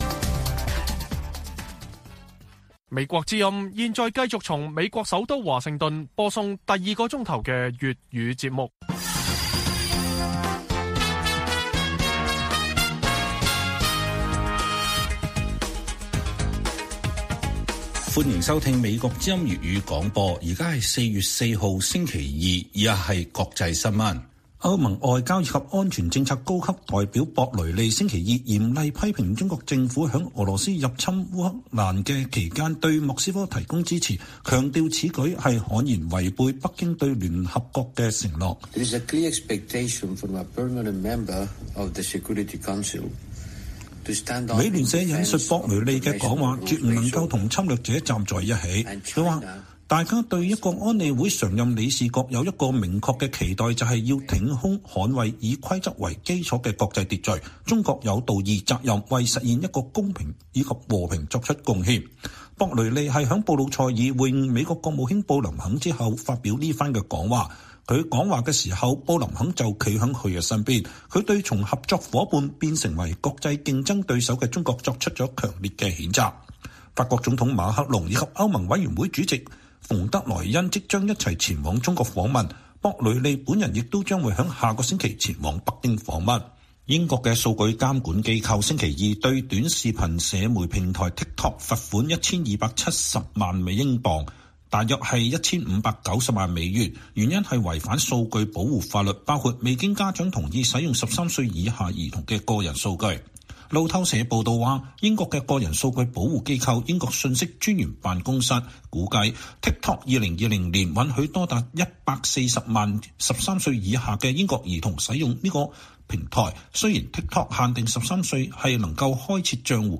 粵語新聞 晚上10-11點: 歐盟對外政策高級代表再警告北京不得軍援俄羅斯